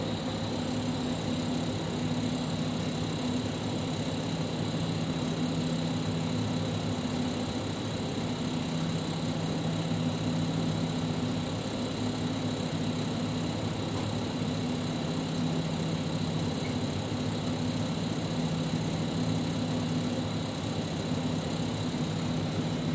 描述：一个嗡嗡的冰箱
Tag: 关闭 开放 哼着 关闭 冰箱 打开门